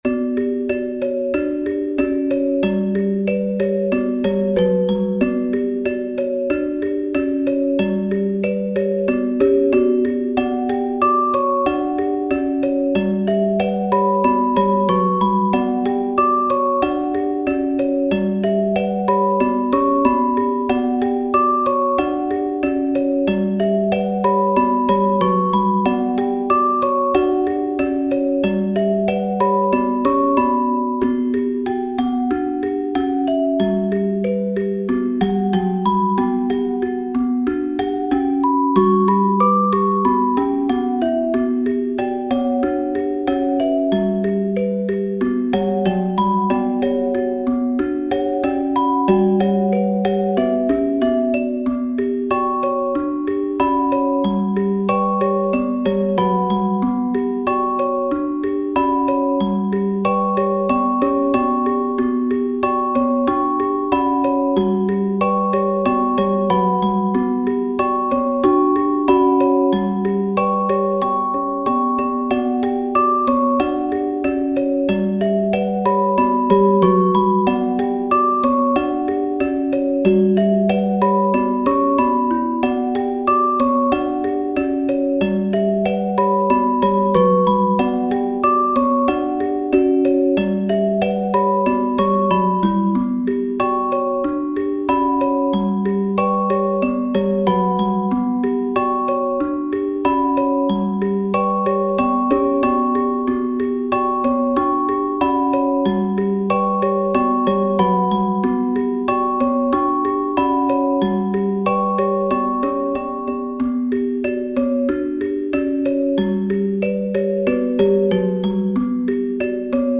縁起が悪い花がテーマの和風オルゴール楽曲集第二弾。
短音階から7度を抜いて使用しており、対位法的なアプローチで旋律を組み合わせることで楽曲を展開している。